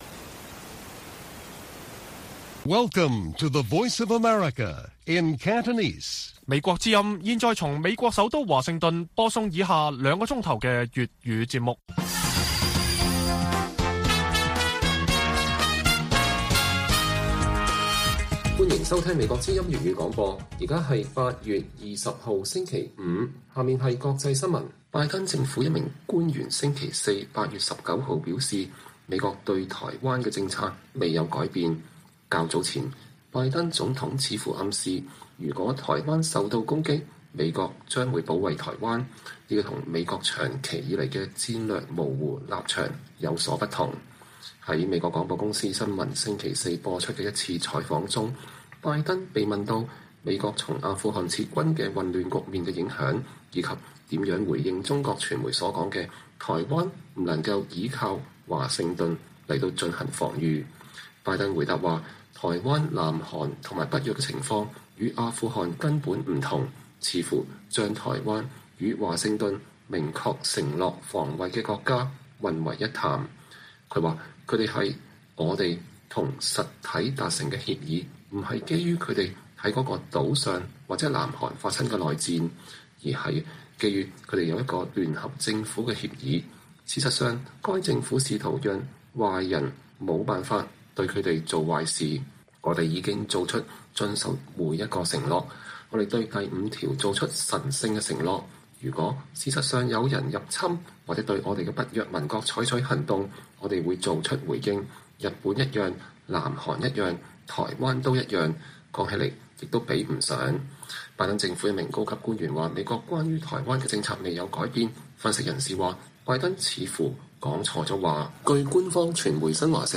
粵語新聞 晚上9-10點: 香港民調指20%受訪者有計劃永久離開